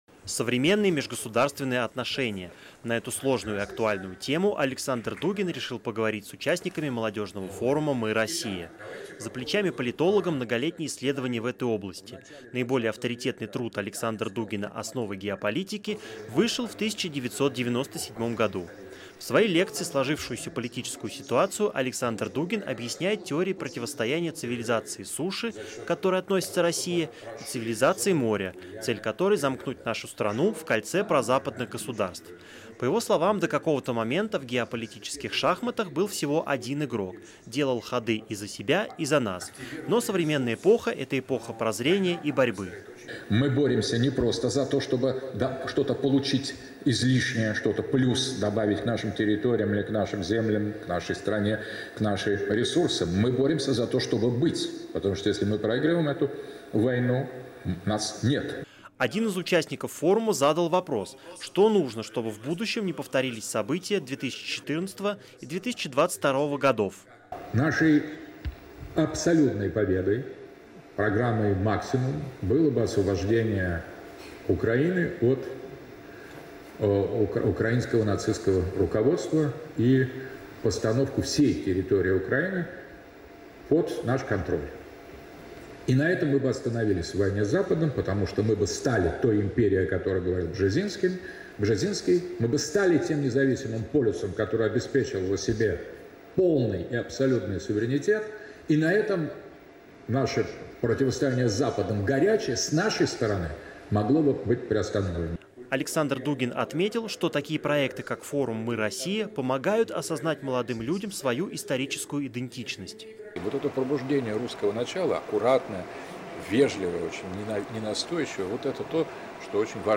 Александр Дугин на форуме «Мы — Россия»